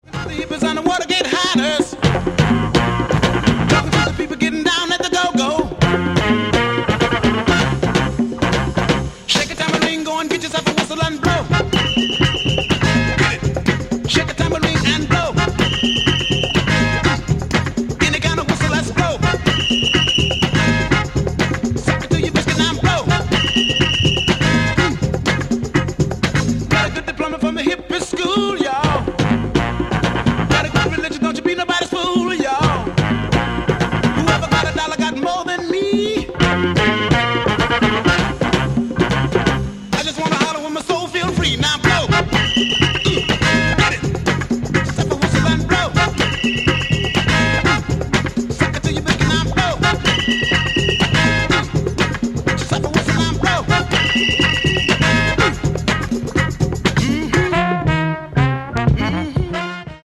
much-sampled classic disco-funk